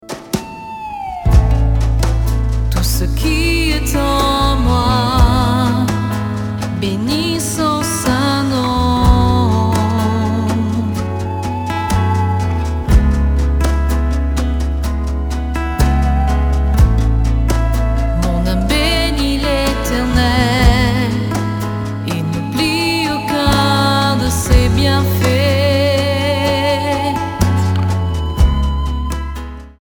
Clavier & Arrangeur
Basse
Guitare
Batterie & Percussions